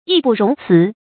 yì bù róng cí
义不容辞发音
成语正音 不，不能读作“bú”。